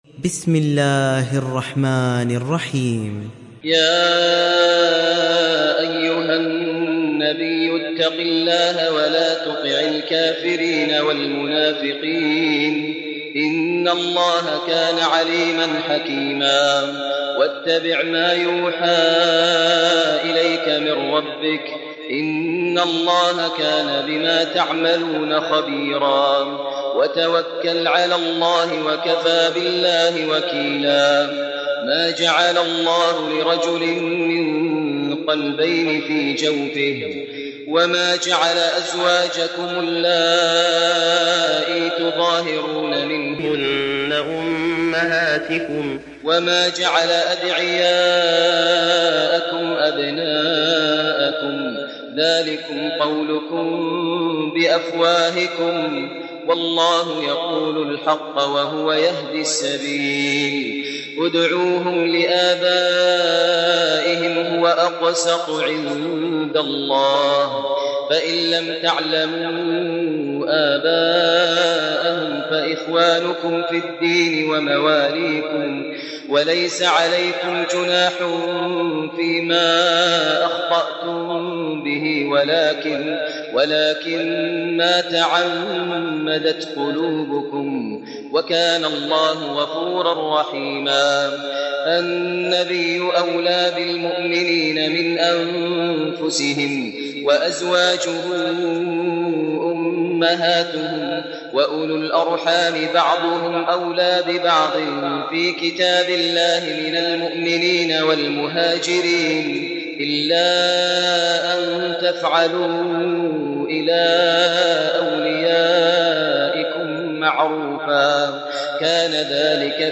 تحميل سورة الأحزاب mp3 بصوت ماهر المعيقلي برواية حفص عن عاصم, تحميل استماع القرآن الكريم على الجوال mp3 كاملا بروابط مباشرة وسريعة